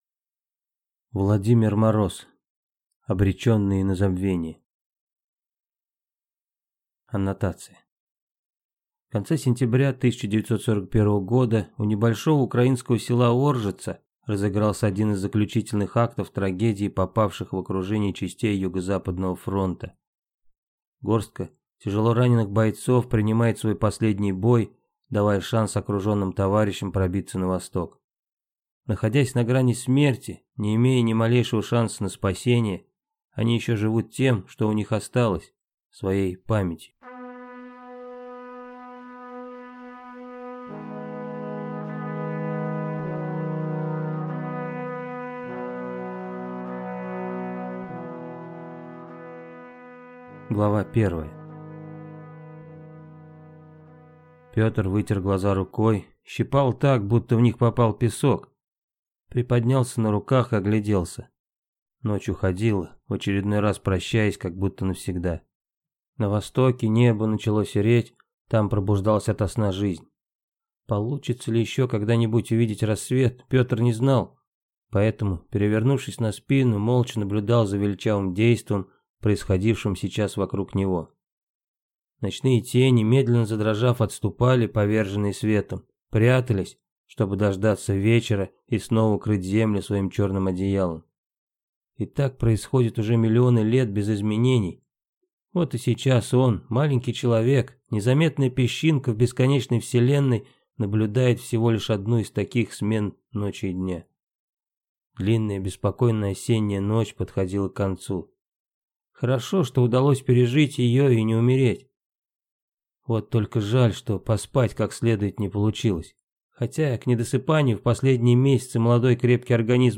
Аудиокнига Обреченные на забвение | Библиотека аудиокниг